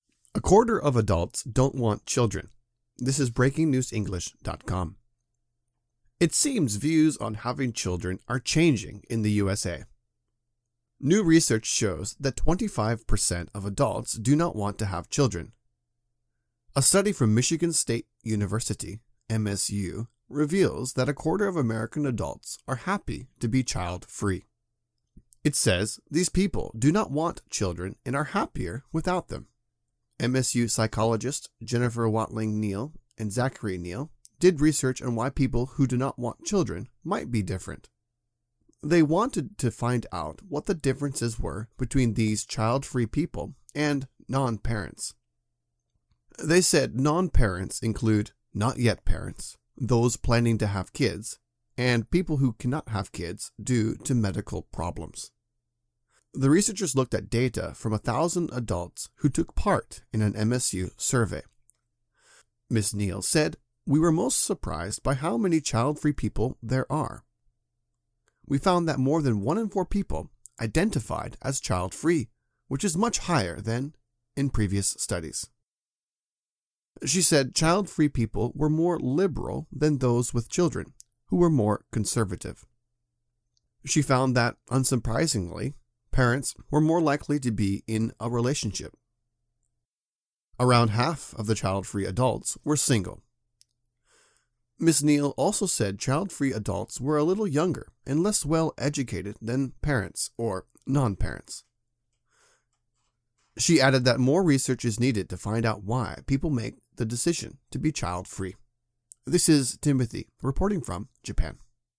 AUDIO (Normal)